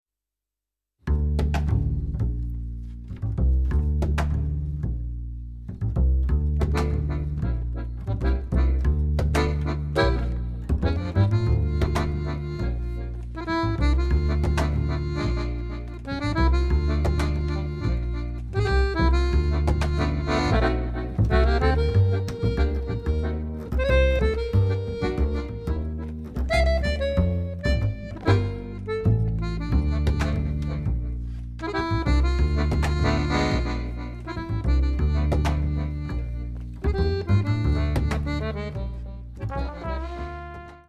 Accordéon / Ténor Lyrique
Trombone
Contrebasse
au Studio Les Tontons Flingueurs (Renaison - France)